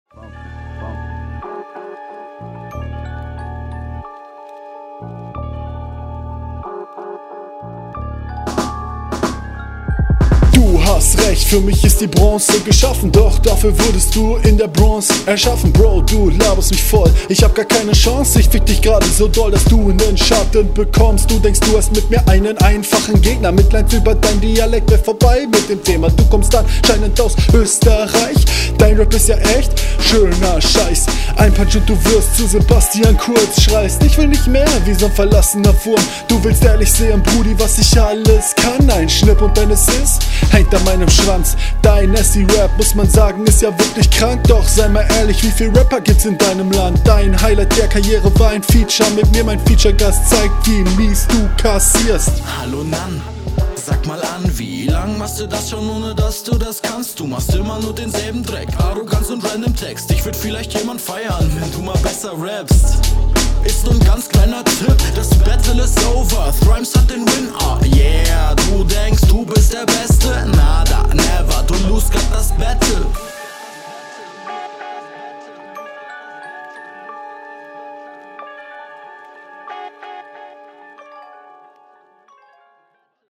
Audio quali deutlich besser!
Flowlich viel weniger probiert als in der Hinrunde und trotzem eher mau.